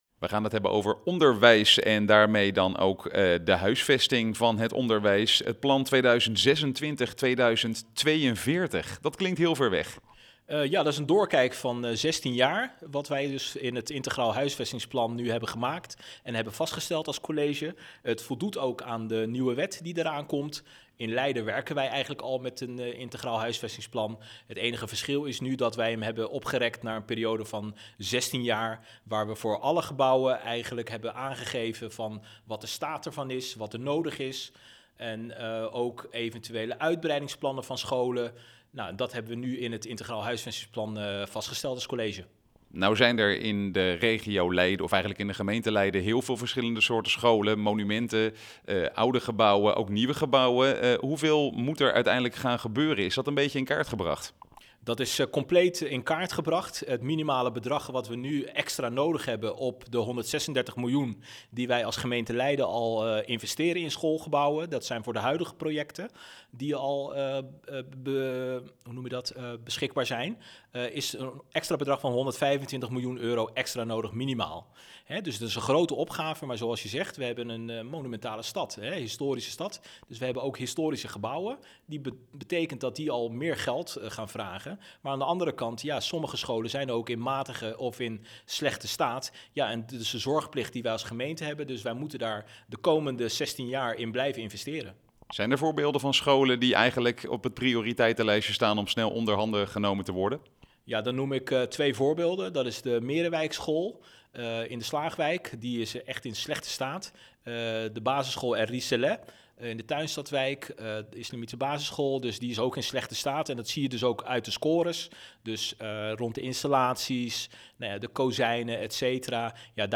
Wethouder Abdelhaq Jermoumi in gesprek met verslaggever